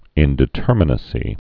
(ĭndĭ-tûrmə-nə-sē)